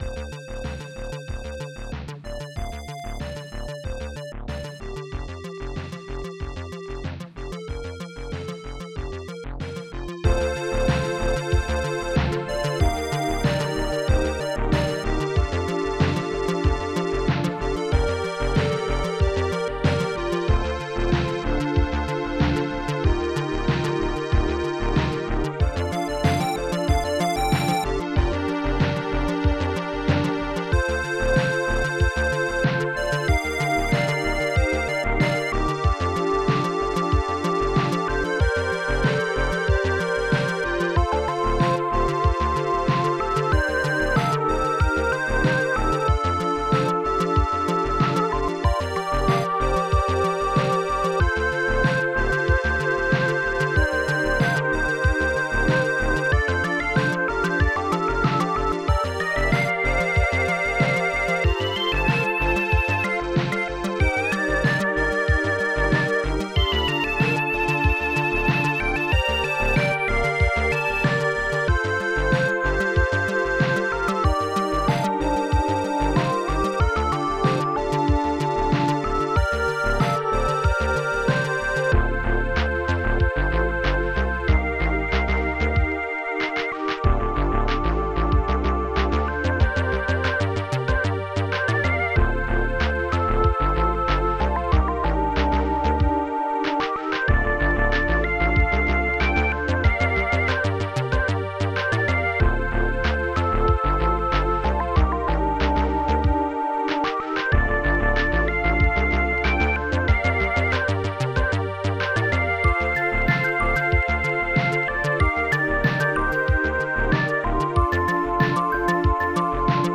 Protracker Module  |  1995-01-01  |  78KB  |  2 channels  |  44,100 sample rate  |  2 minutes, 23 seconds
st-92:tubestring
st-92:organlo
st-92:organhi
st-92:wowbass4
st-90:breaksnareclap
st-90:breakbdrum
st-92:strings8-acc1
st-93:mallet
st-90:m1claps